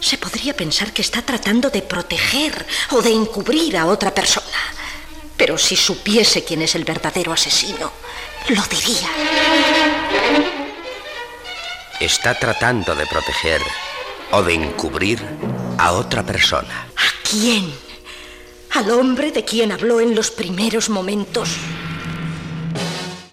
Fragment d'un episodi del serial radiofònic